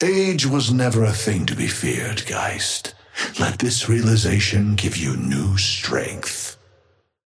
Amber Hand voice line - Age was never a thing to be feared, Geist. Let this realization give you new strength.
Patron_male_ally_ghost_oathkeeper_5i_start_04.mp3